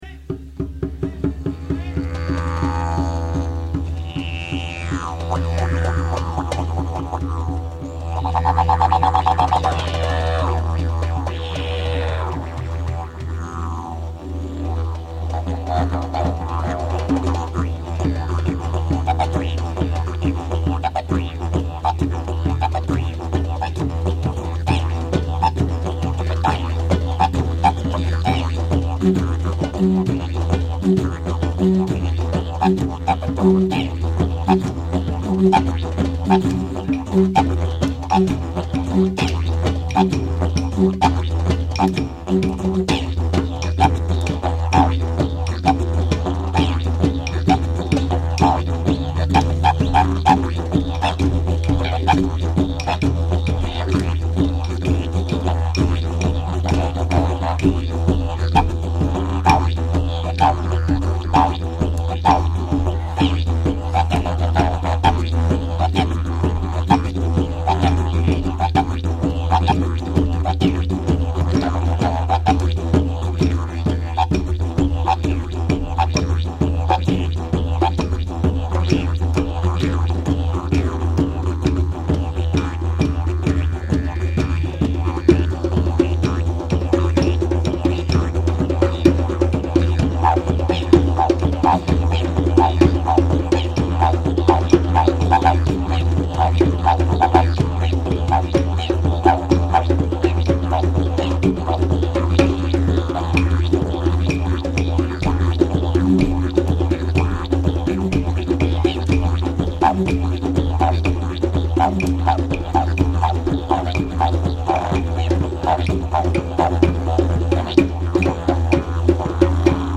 petit morceau du concert le mix n'est pas encore fait soyez indulgent pour la qualité sonore merci et bonne écoute.